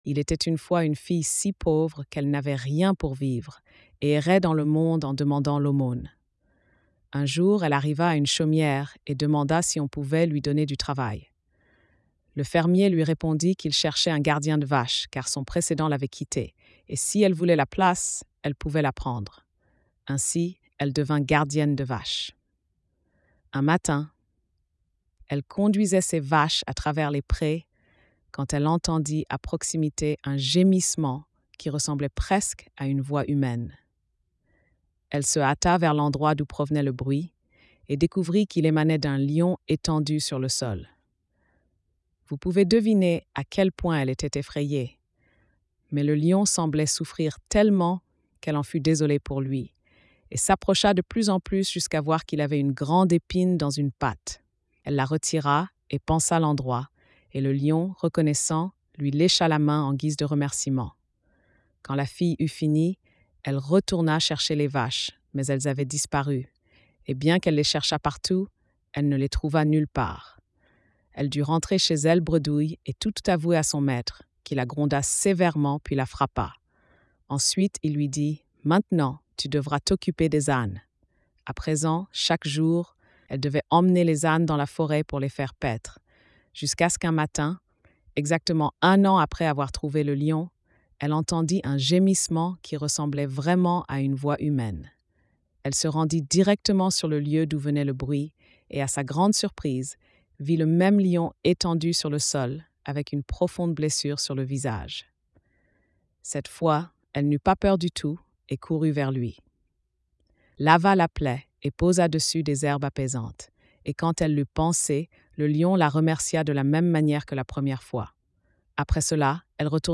Le Lion Blessé - conte à lire ou écouter seul-e, en famille ou en classe.